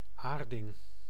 Ääntäminen
IPA: [ˈeːɐ̯.də] Tuntematon aksentti: IPA: /ˈʔeːɐ̯də/